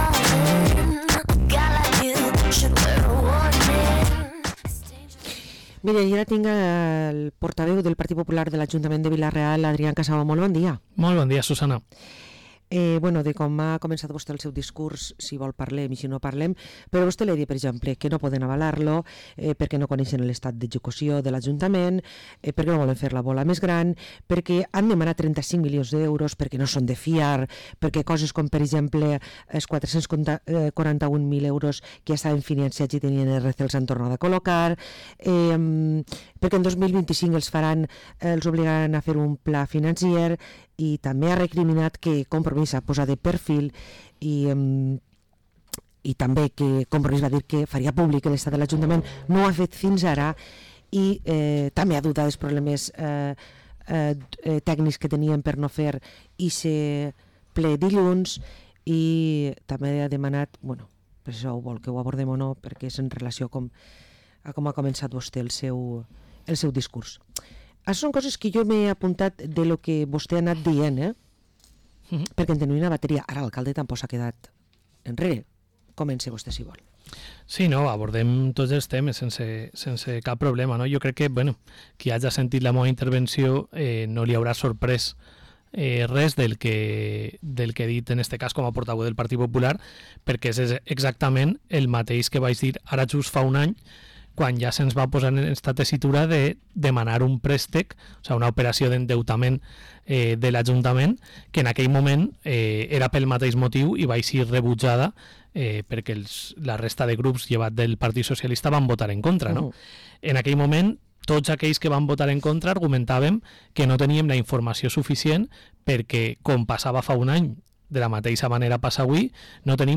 Adrián Casabó, portaveu del PP a Vila-real, fa valoració del ple extraordinari de hui 20 de novembre